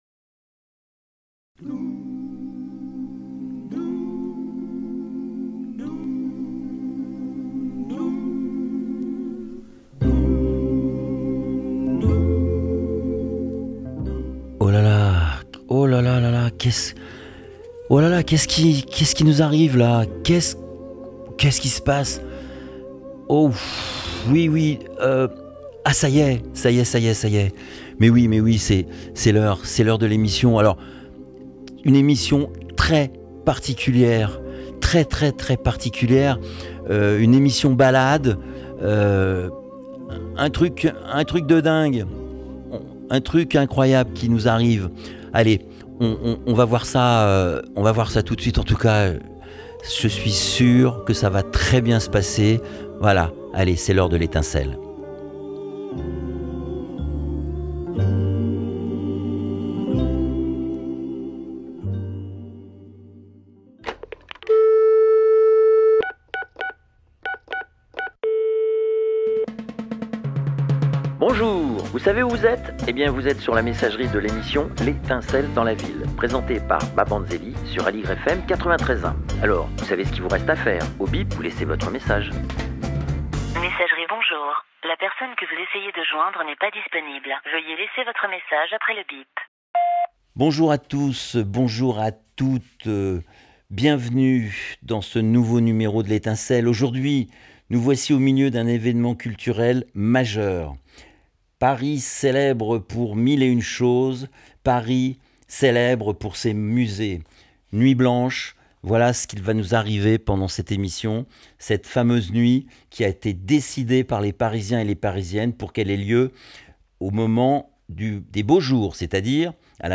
Musiques de l'émission DanceHall Reggae Vybz